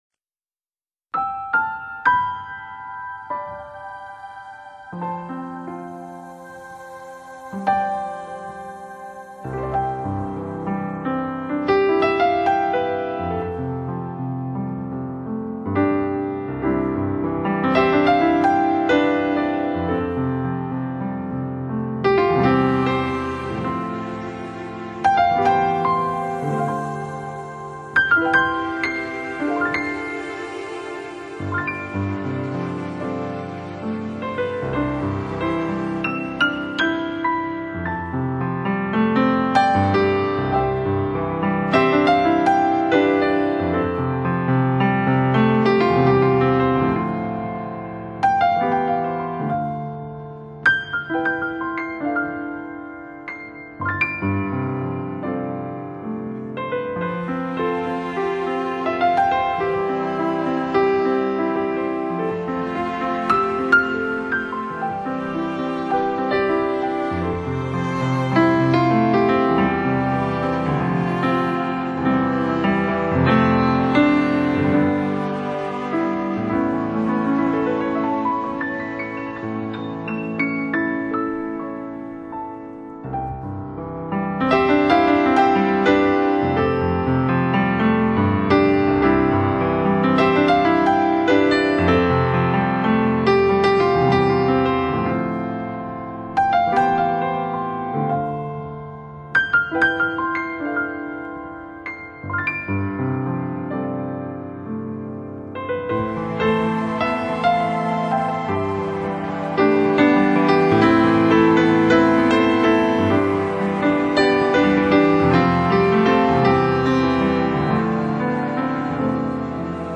在钢琴固有的主题下，更增加了其他乐器搭配的比重。
钢琴的每一个琴键炫出的音符，都有如绵绵的细雨，每一滴都可以滋润你的枯燥，